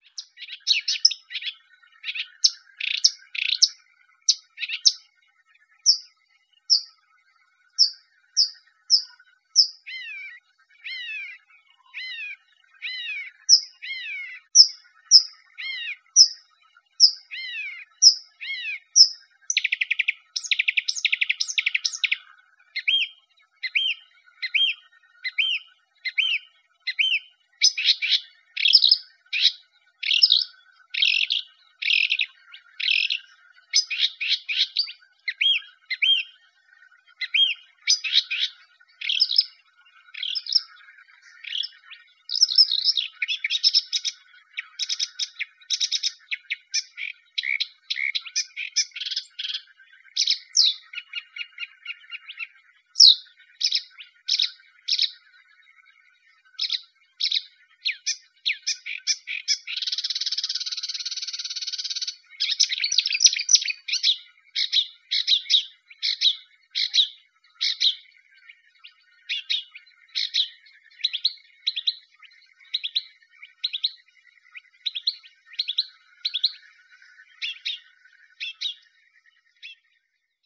SUMPFROHRSÄNGER, Gesang 2   >